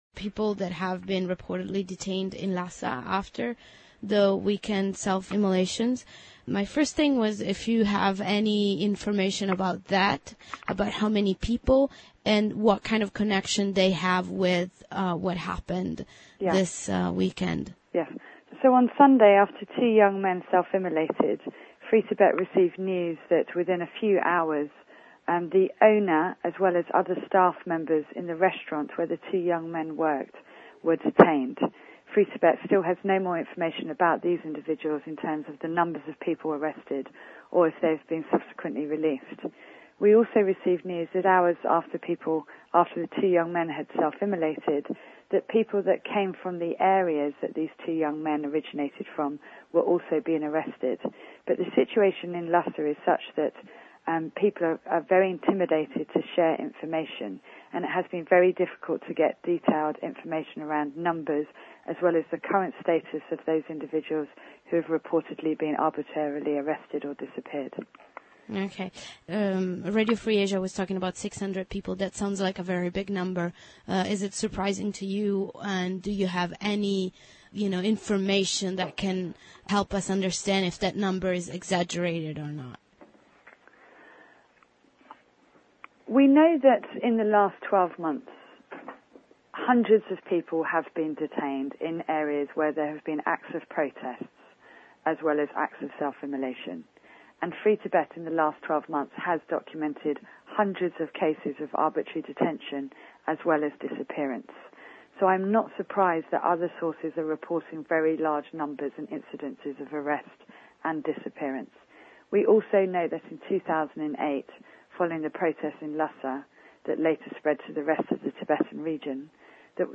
Q&A on Tibet Immolations